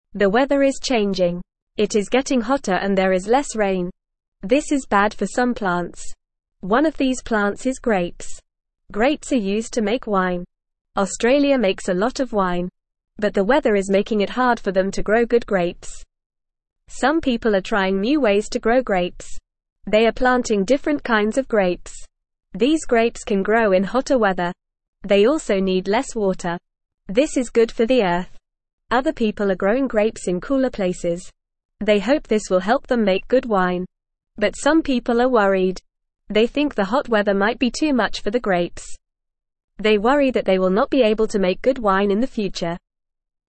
Fast
English-Newsroom-Beginner-FAST-Reading-Hot-Weather-Makes-Growing-Grapes-Harder.mp3